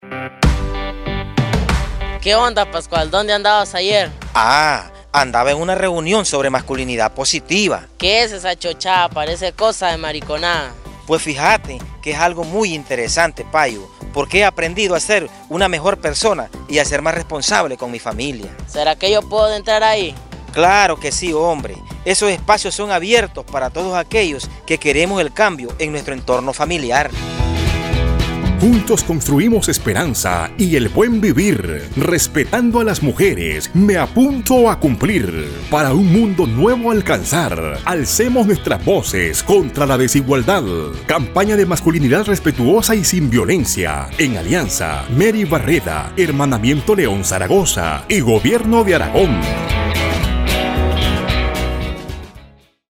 Importante destacar la participación protagónica de hombres de las comunidades del sector rural noreste de León.